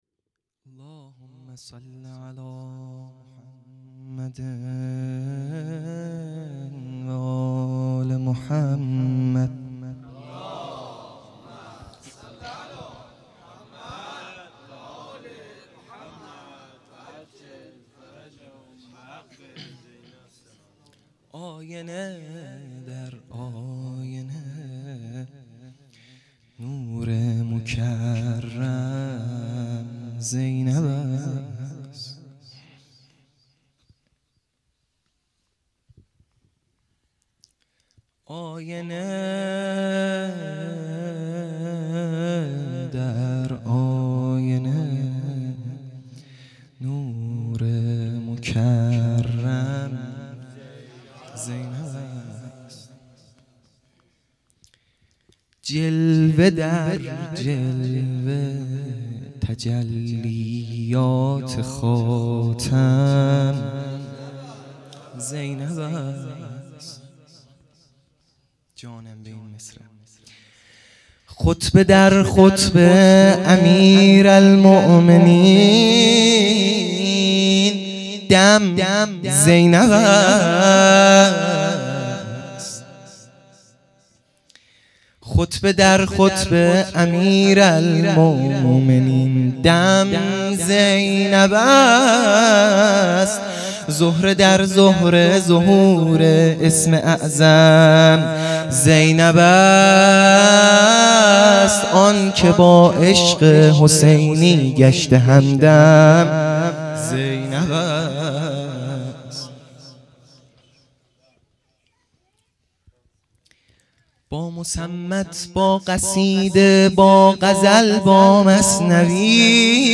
مدح | آیینه در آیینه نو رمکرم زینب است